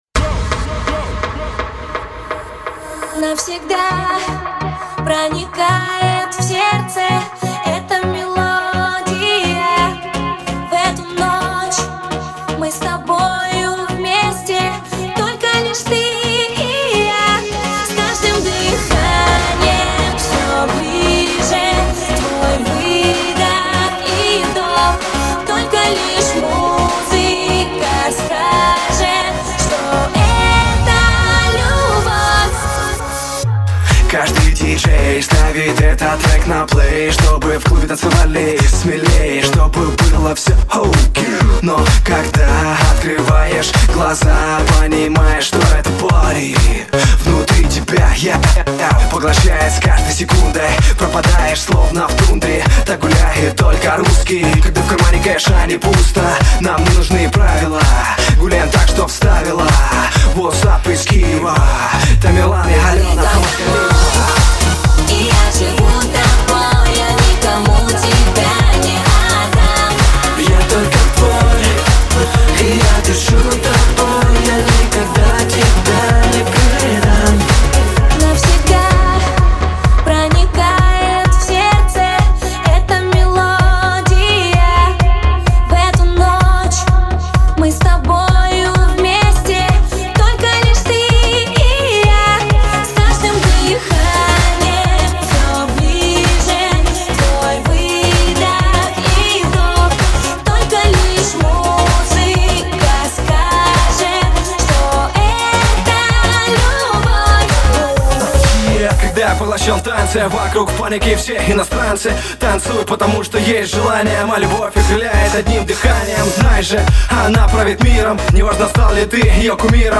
Категория: Drum'n'Bass